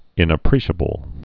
(ĭnə-prēshə-bəl)